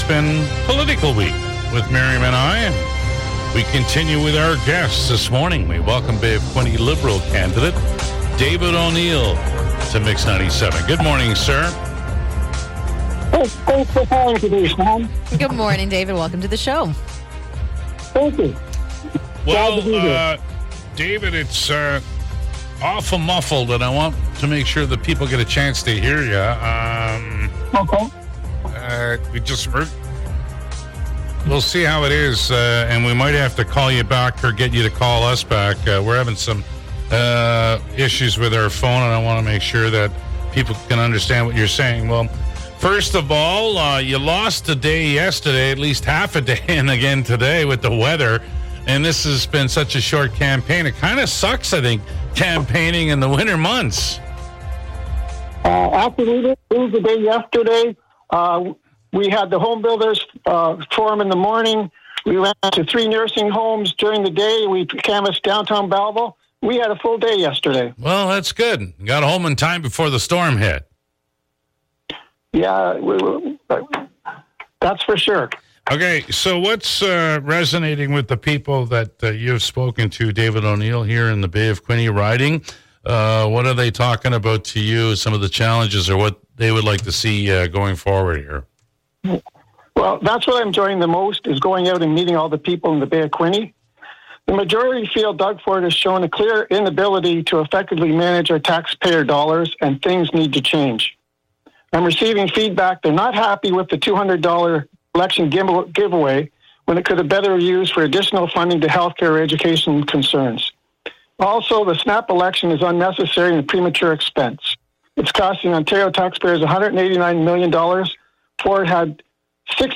Day 3 of Political Week on the MIX! The Morning Crew talk with Liberal candidate David O’Neil